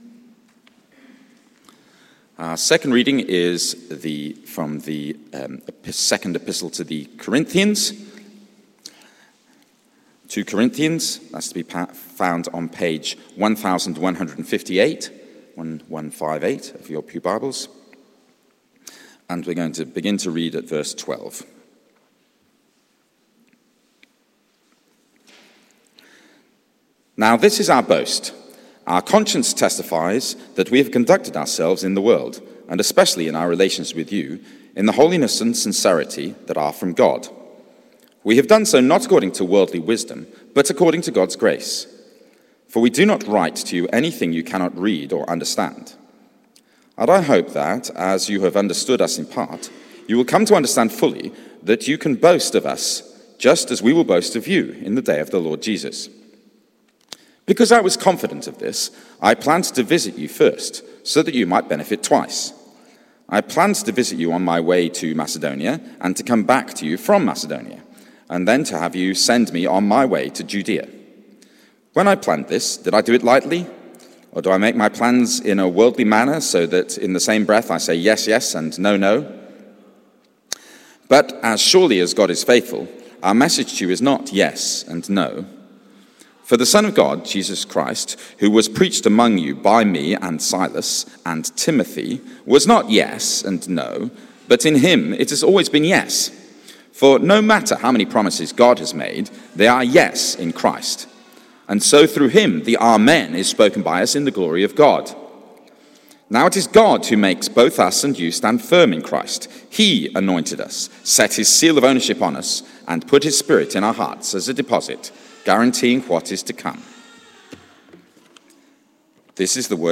Theme: Faithful or Fickle Sermon